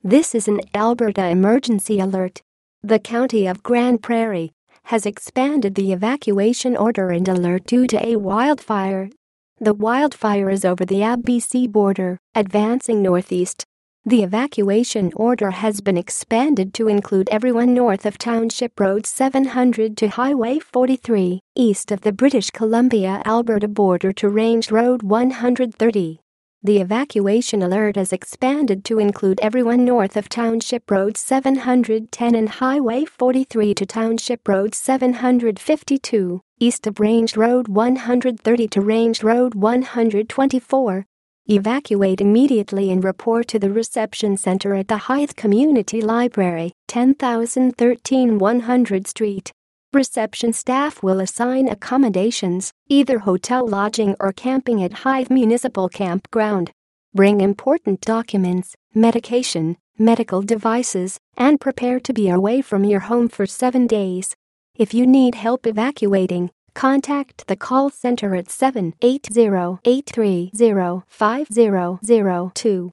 Broadcast Audio